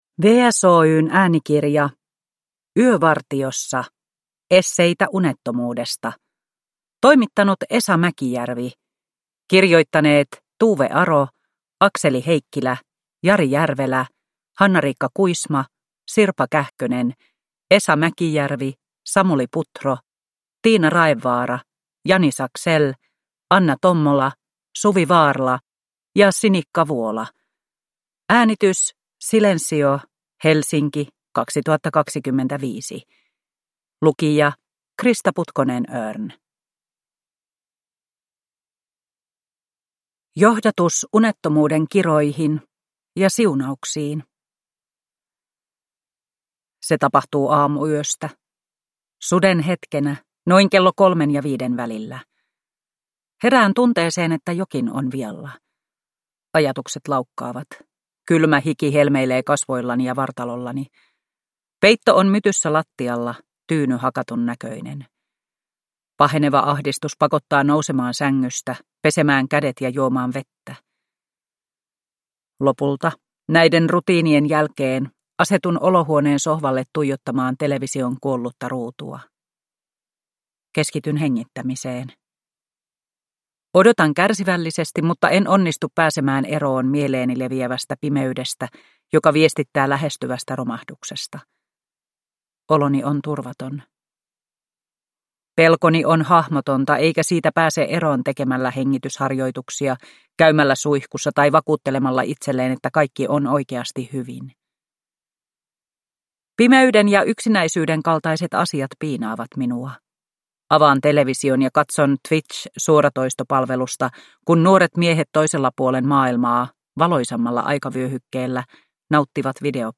Yövartiossa (ljudbok) av Jari Järvelä